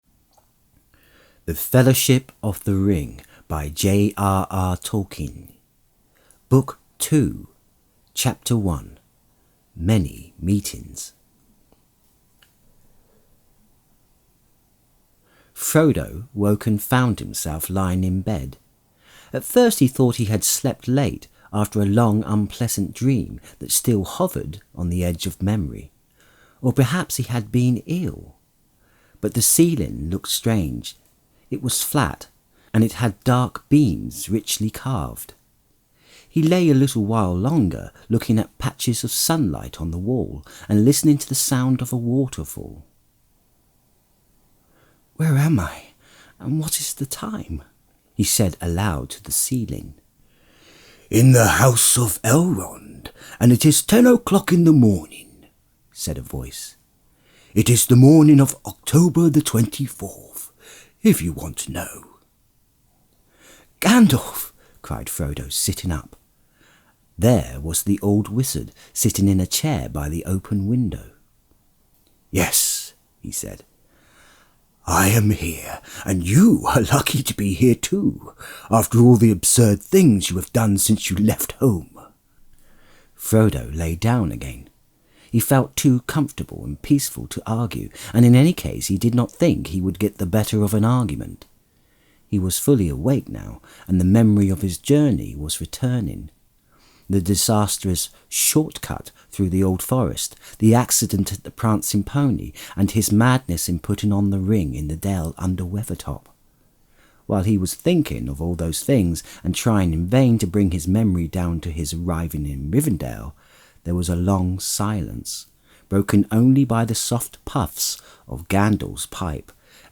Lord Of The Rings And Hobbit Audiobooks (J.R.R. Tolkien)! Podcast - The Fellowship of the Ring - Book Two | Chapter 1: Many Meetings (J.R.R. Tolkien) | Free Listening on Podbean App